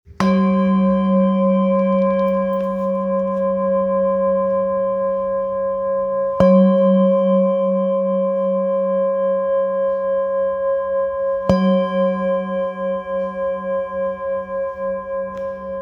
Singing Bowl, Buddhist Hand Beaten, with Fine Etching Carving of Ganesh, 19 by 19 cm,
Material Seven Bronze Metal